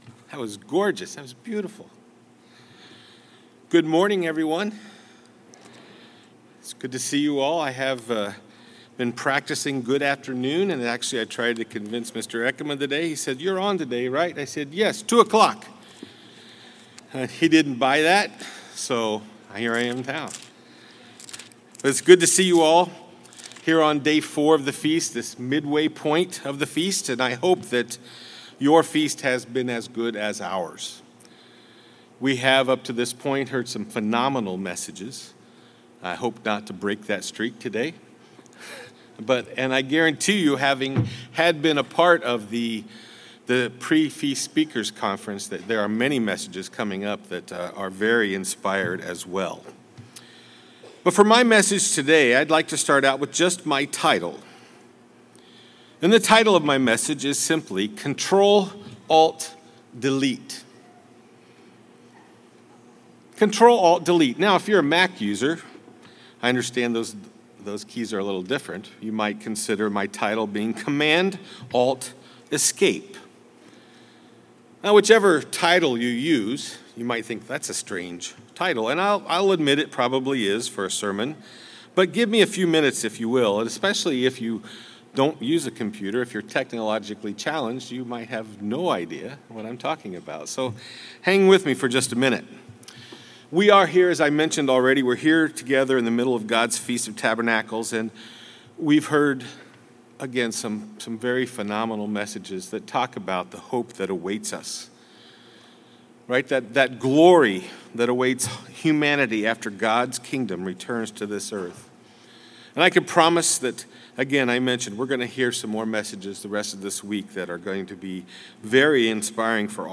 Ctrl + Alt + Del might sound like a funny title for a split sermon, but in this message we look at how this computer key combo can be paralleled to our spiritual walk with God.
This sermon was given at the Branson, Missouri 2021 Feast site.